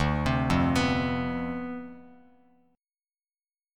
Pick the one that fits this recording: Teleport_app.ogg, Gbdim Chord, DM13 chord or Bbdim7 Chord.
DM13 chord